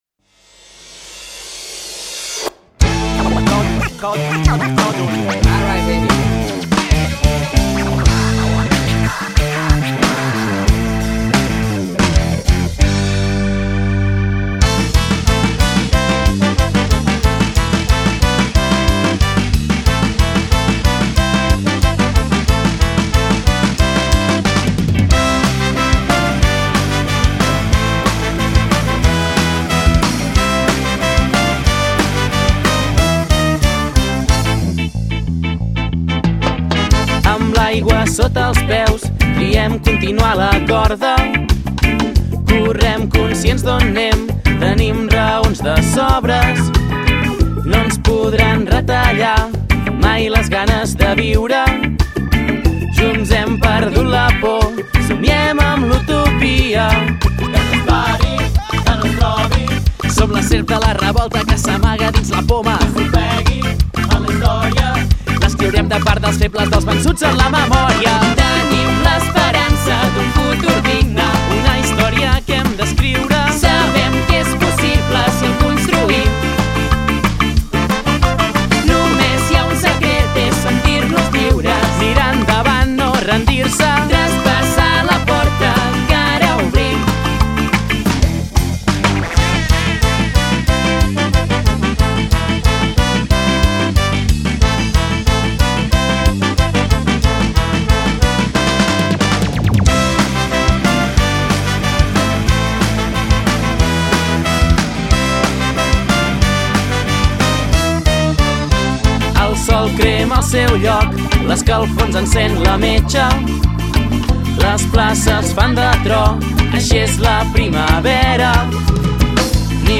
la suma de ska festiu amb rock.